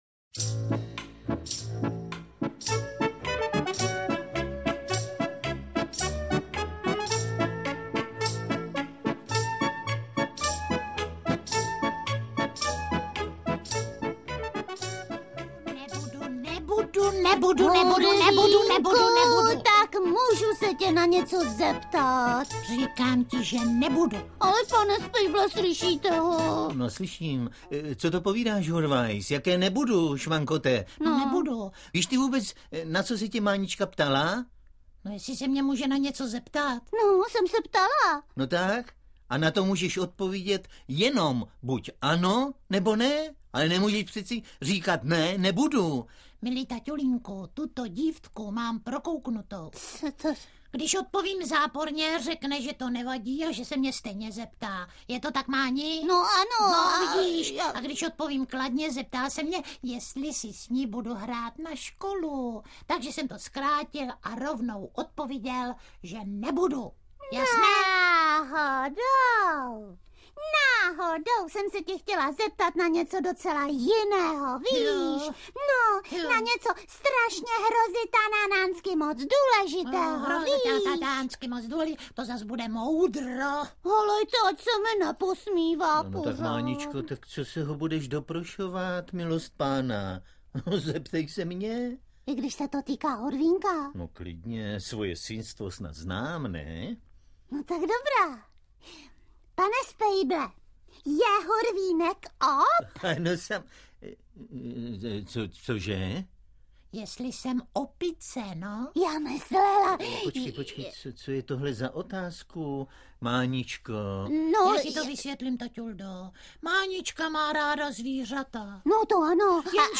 Další titul archivních nahrávek protagonistů Divadla S+H /bývalého ředitele a dlouholetého interpreta Spejbla a Hurvínka a současné ředitelky divadla, Heleny Štáchové, interpretky Máničky a tety Kateřiny/ nabízí čtyři scénky, jejichž společným jmenovatelem je Hurvínkovo "objevování" přírody. Že se tak děje za účasti taťuldy Spejbla a všudypřítomné Máničky a je při tom spousta legrace, není třeba dodávat.
Audiokniha
Čte: Miloš Kirschner, Helena Stachová